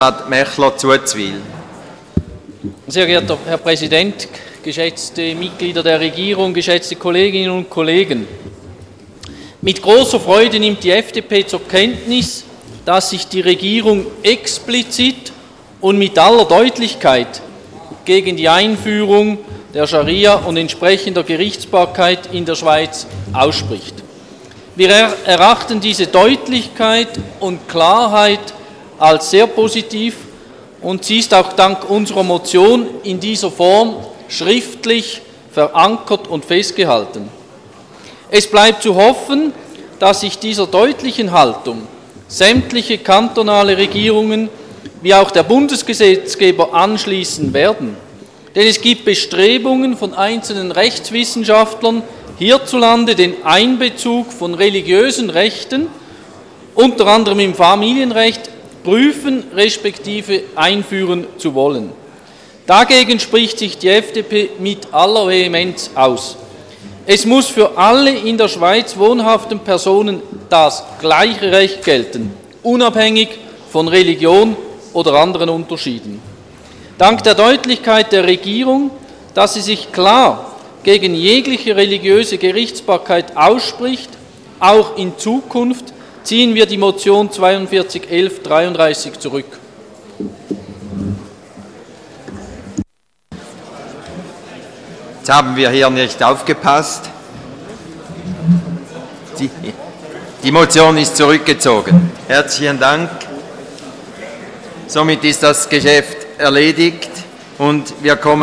25.9.2012Wortmeldung
Session des Kantonsrates vom 24. und 25. September 2012